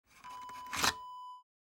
Camcorder tape remove sound effect .wav #2
Description: The sound of removing a tape from a camcorder
Properties: 48.000 kHz 24-bit Stereo
A beep sound is embedded in the audio preview file but it is not present in the high resolution downloadable wav file.
camcorder-tape-remove-preview-2.mp3